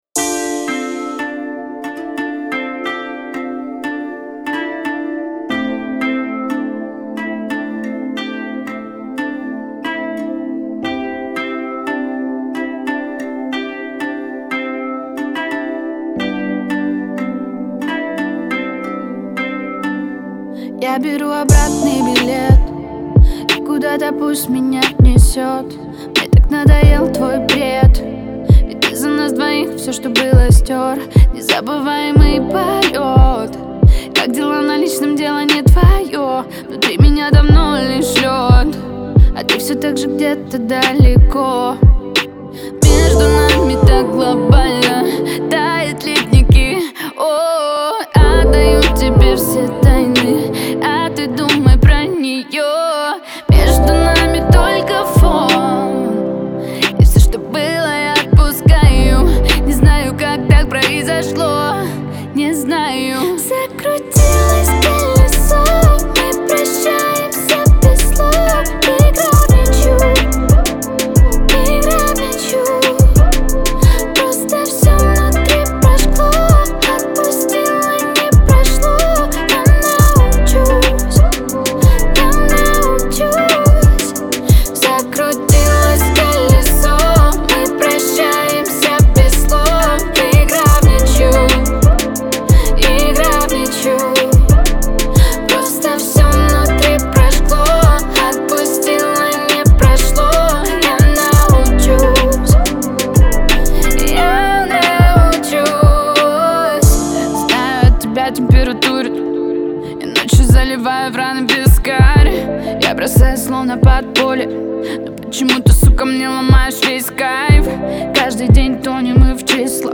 яркая и энергичная песня
выполненная в жанре поп-рок.